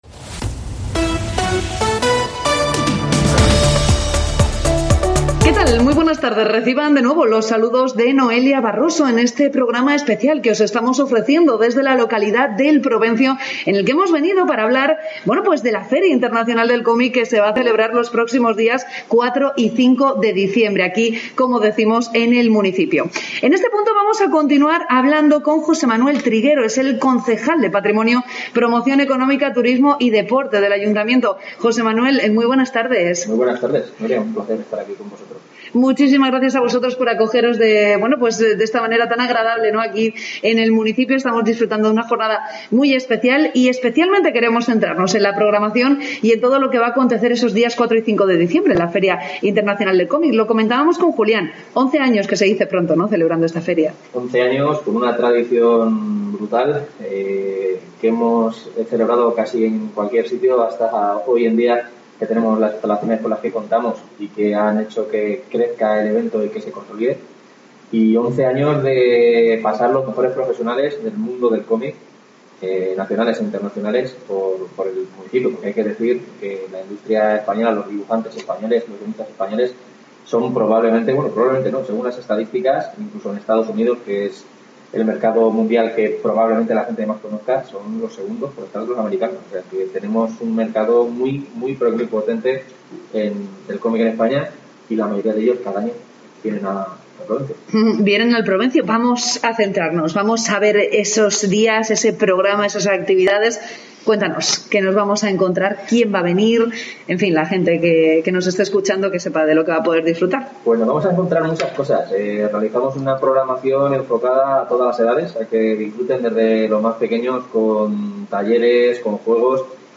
Mediodía COPE Cuenca desde El Provencio